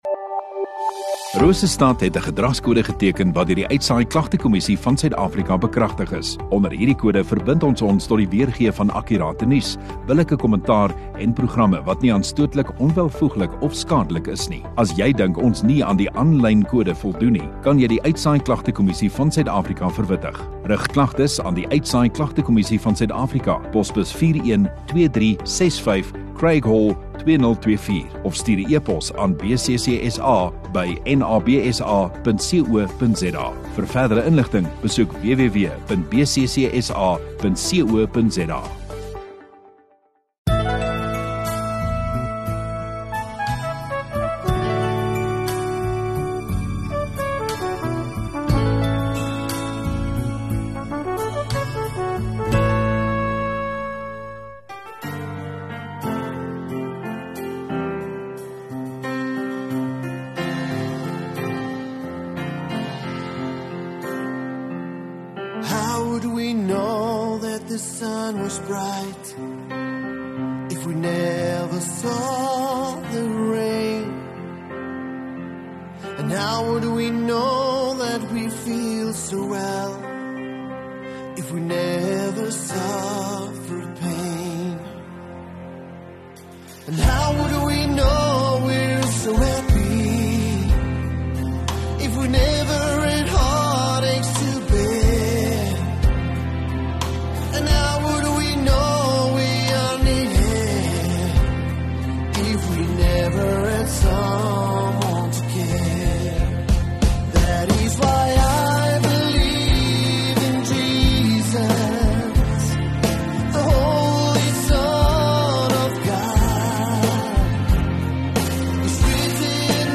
View Promo Continue Install Rosestad Godsdiens 15 Oct Sondagoggend erediens